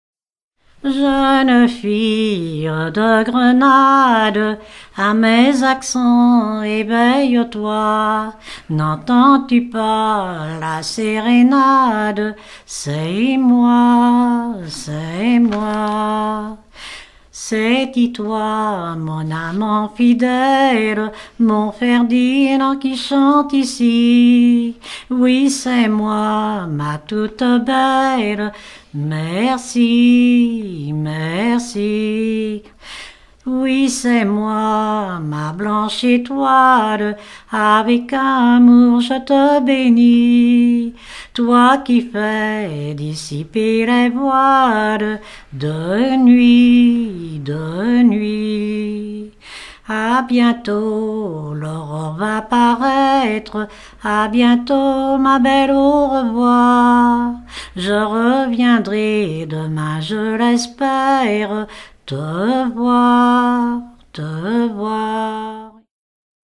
Chants brefs - De noces
Pièce musicale éditée